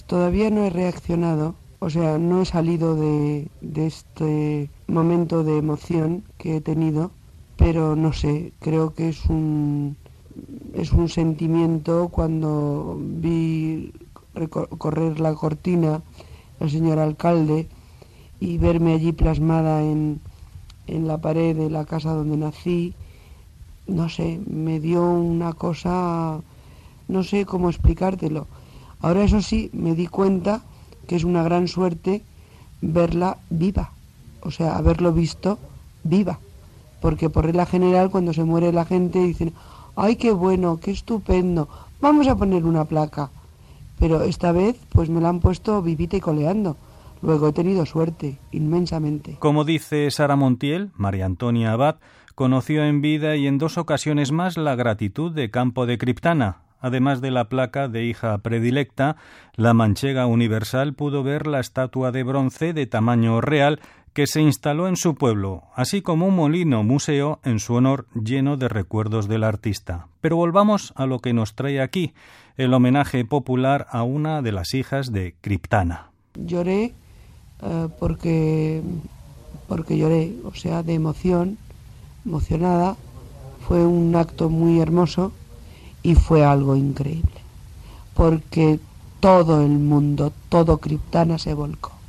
Entrevista a l'actriu Sara Montiel (María Antonia Abad) poc després de ser nomenda filla predilecta de Campo de Criptana (Ciudad Real)